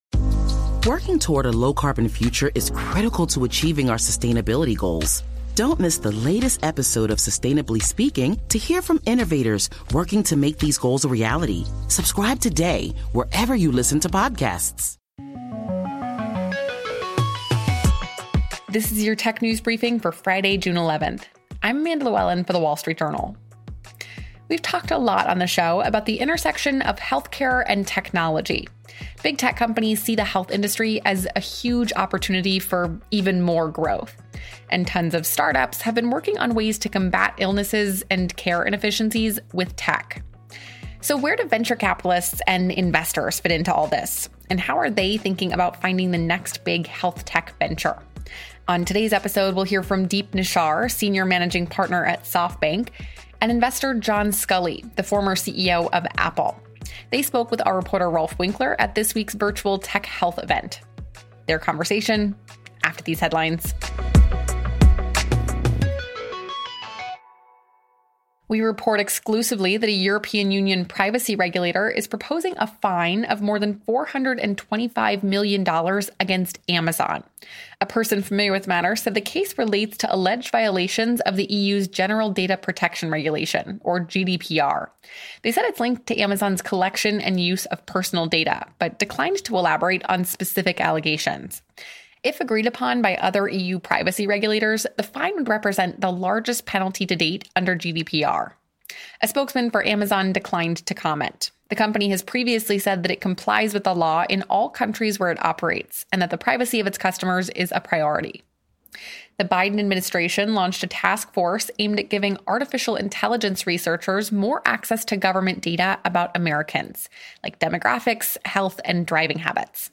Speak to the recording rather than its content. At the WSJ's recent virtual Tech Health event